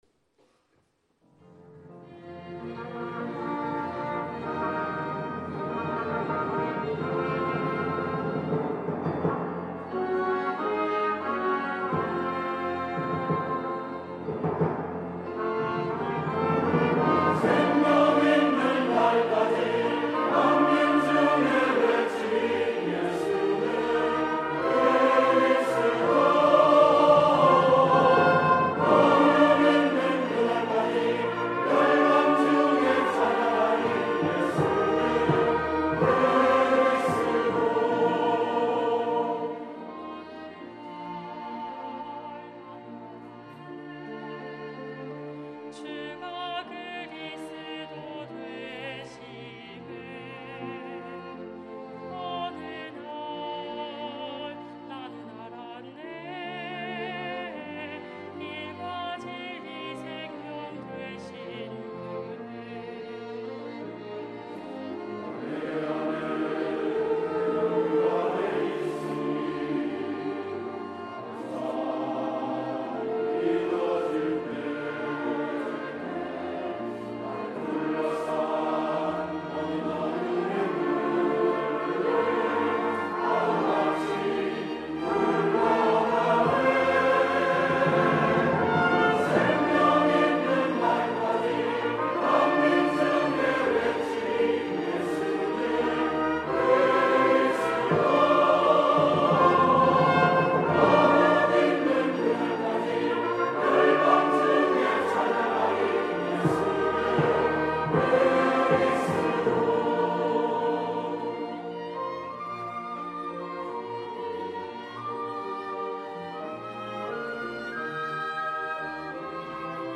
2부 찬양대